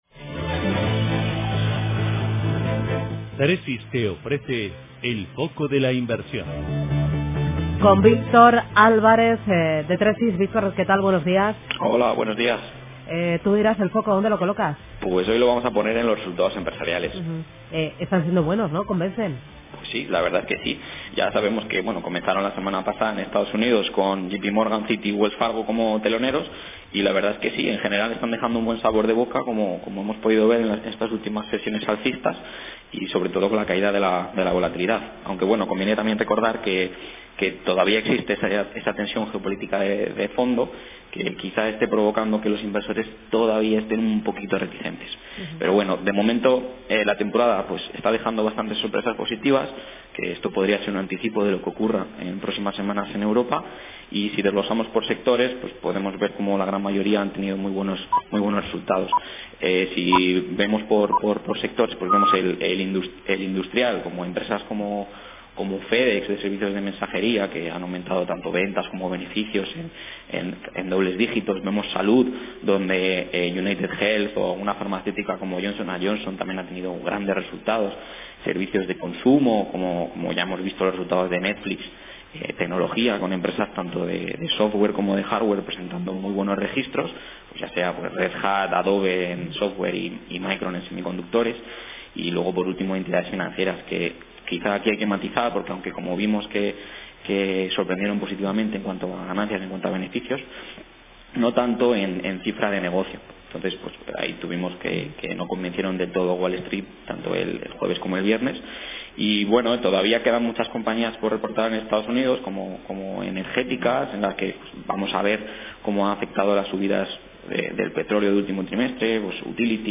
En la radio
En Radio Intereconomía todas las mañanas nuestros expertos analizan la actualidad de los mercados.